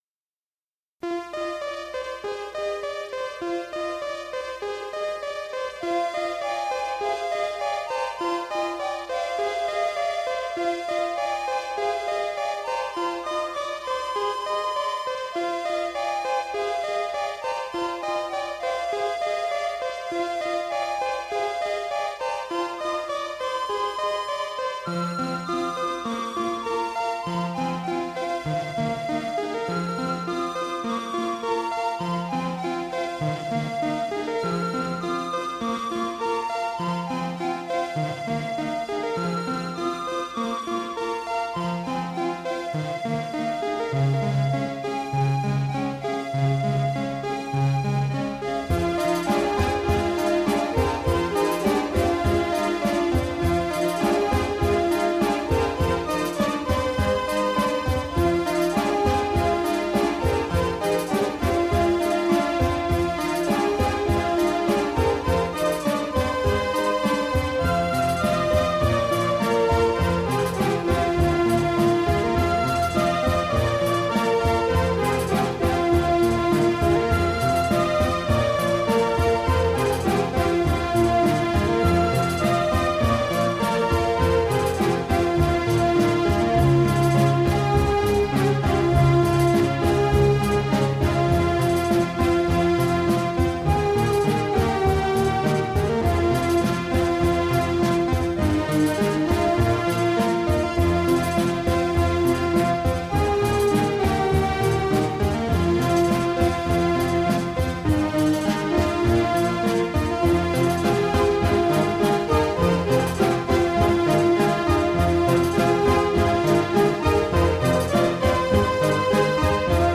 Melodic electronica.
Tagged as: Electronica, Other, Prog Rock